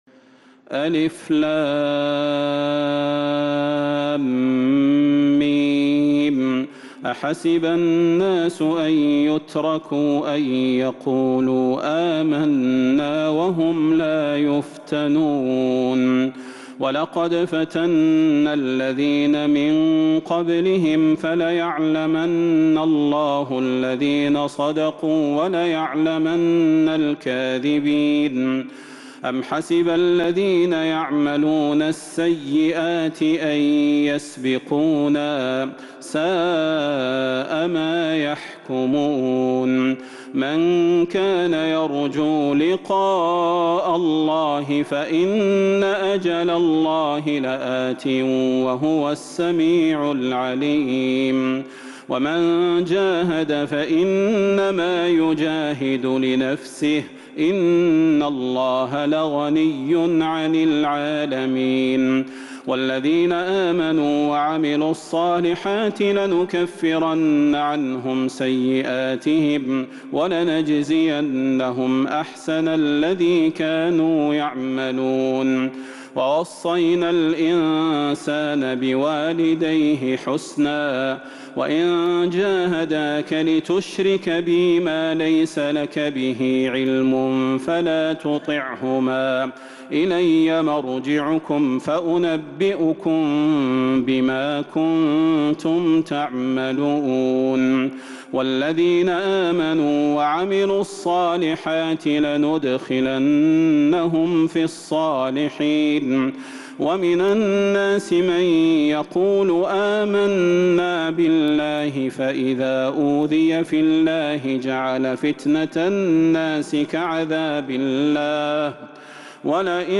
سورة العنكبوت كاملة من تراويح الحرم النبوي 1442هـ > مصحف تراويح الحرم النبوي عام 1442هـ > المصحف - تلاوات الحرمين